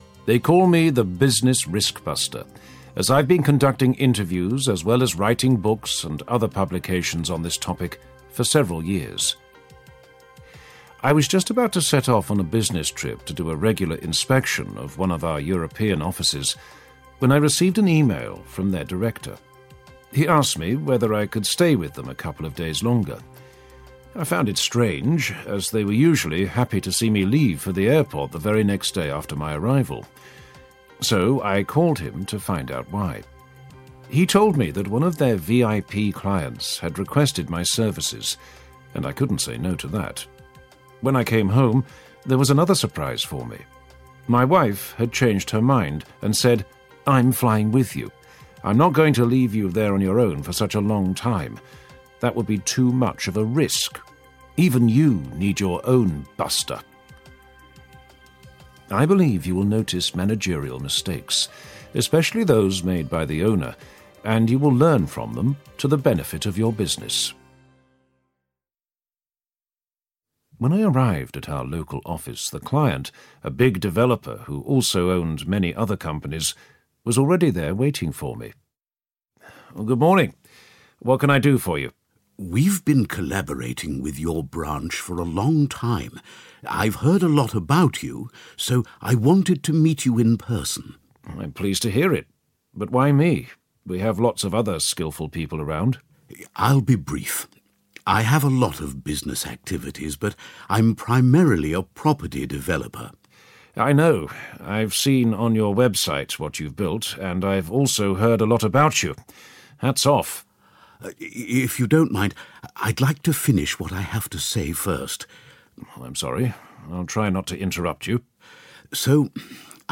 Business Risk Buster Intervenes 6 audiokniha
Ukázka z knihy